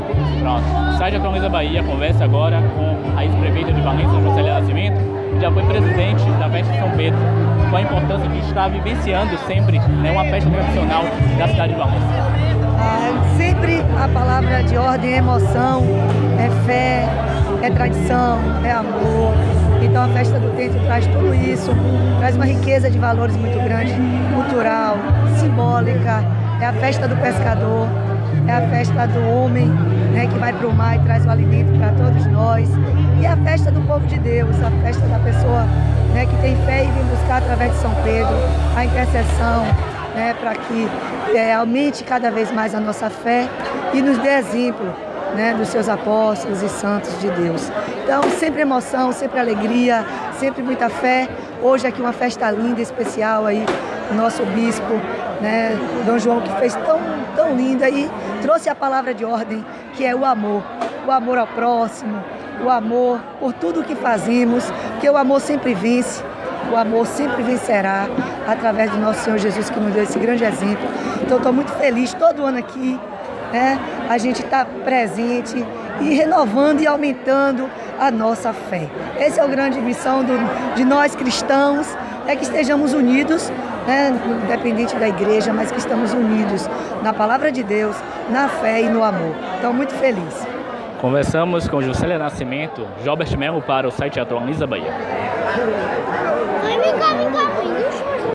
A ex-prefeita Jucélia Nascimento, que já foi presidente da comissão da festa de São Pedro citou a importância de participar do momento: